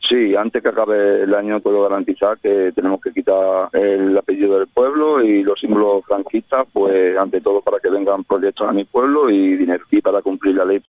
FRANCISCO MORENO (Alcalde electo de Guadiana del Caudillo): "Guadiana perderá su apellido"